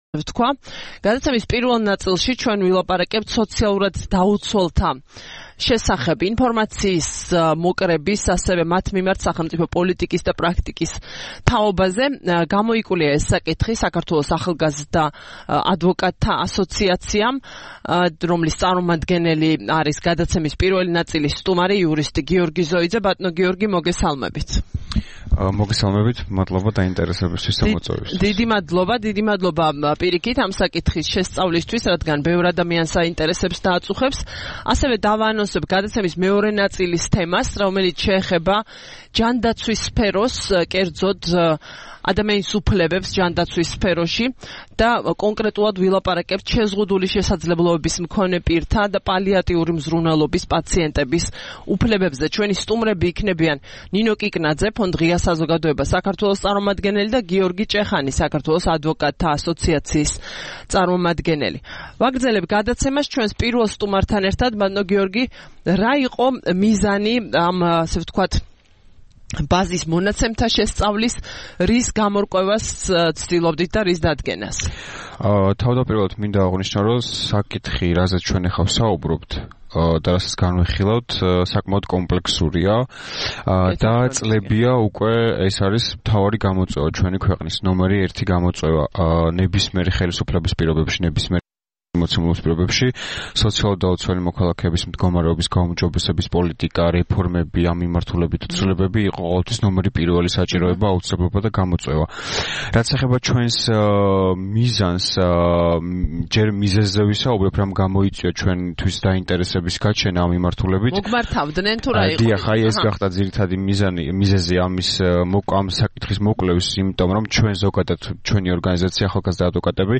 რადიო თავისუფლების "დილის საუბრების" სტუმარი იყო